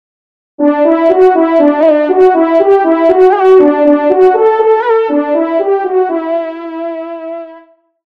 FANFARE
Localisation : Loiret – Eure